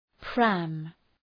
Προφορά
{præm}